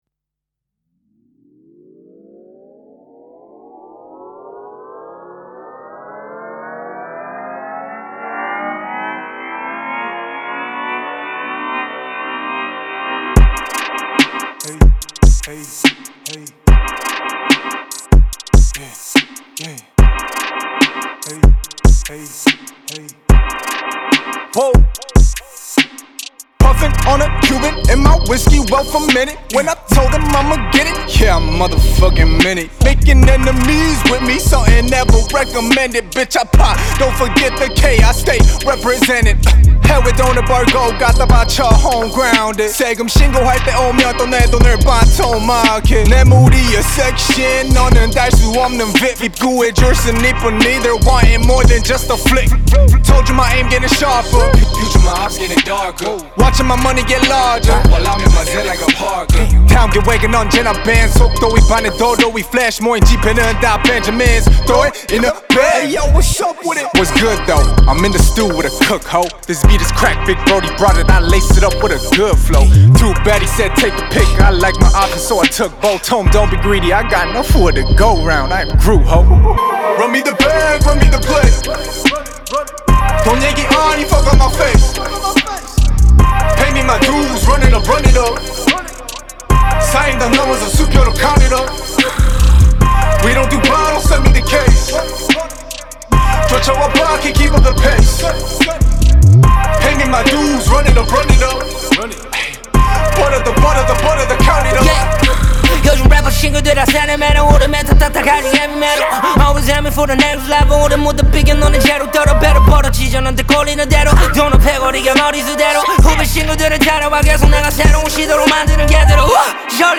• Жанр: Rap